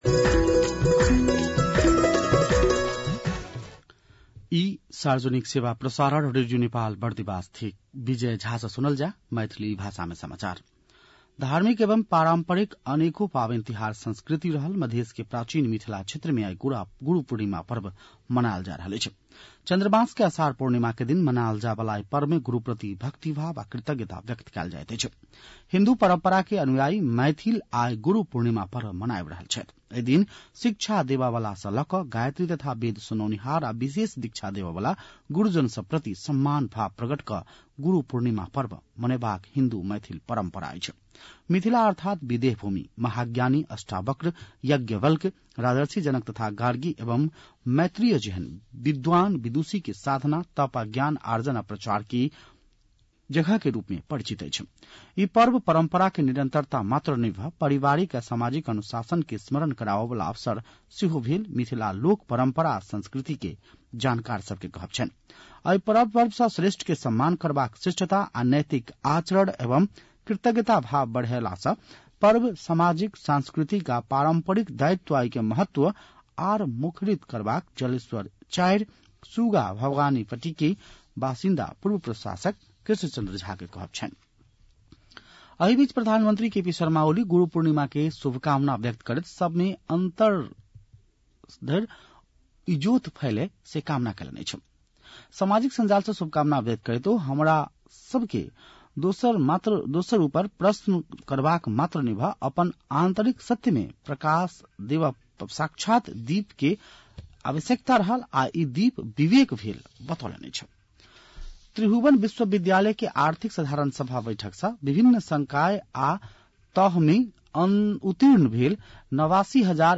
मैथिली भाषामा समाचार : २६ असार , २०८२
Maithali-news-3-26.mp3